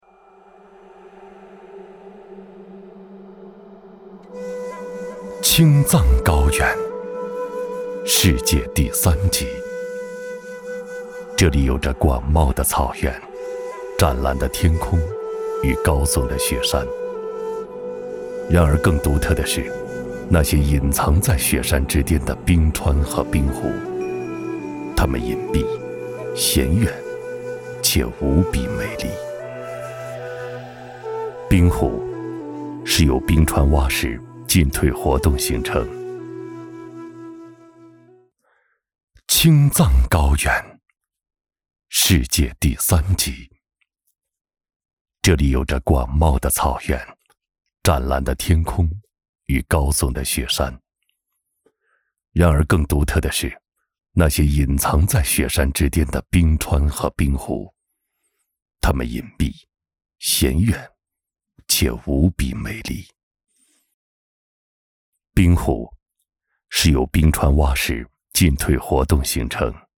纪录片-男29-娓娓道来-危险神秘的冰湖.mp3